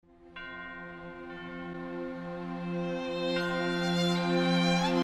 Videogame music and sound effects